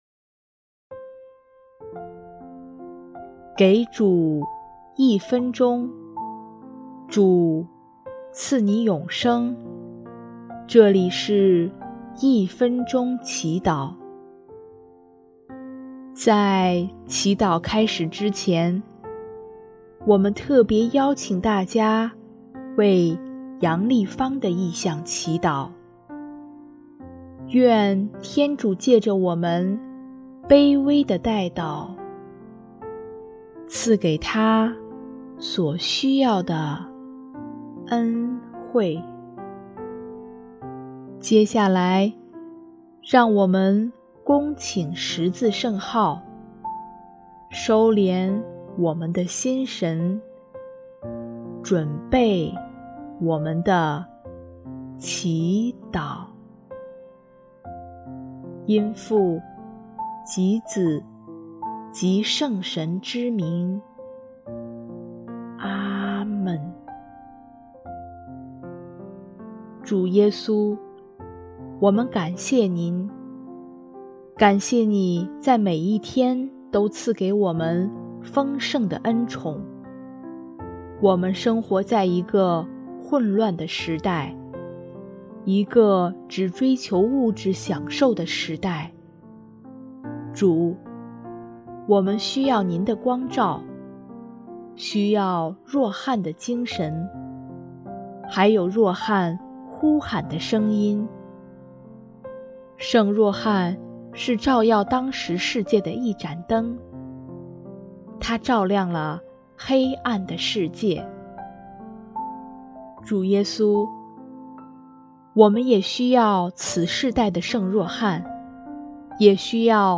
【一分钟祈祷】|6月24日 化作此世的圣若翰，呼喊与照耀